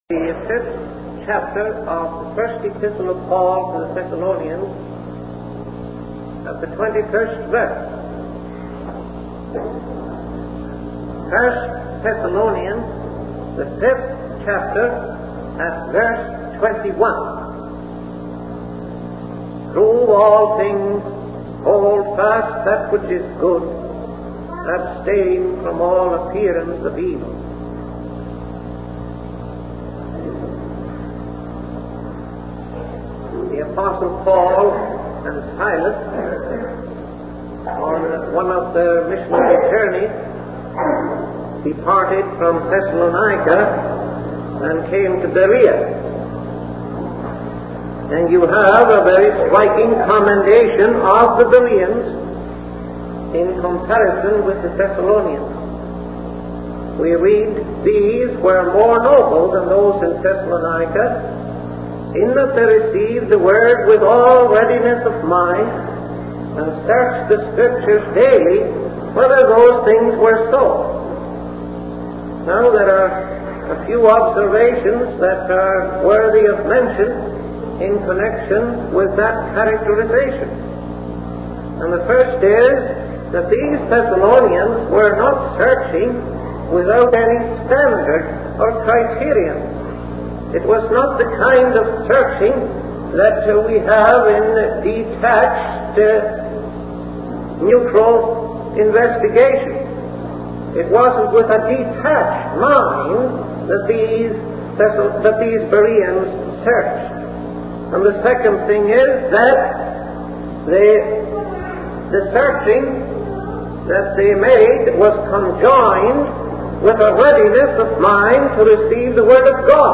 In this sermon, the preacher emphasizes the importance of three exercises of the mind: proving, holding, and abstaining. He explains that these exercises are crucial in navigating the challenges of life.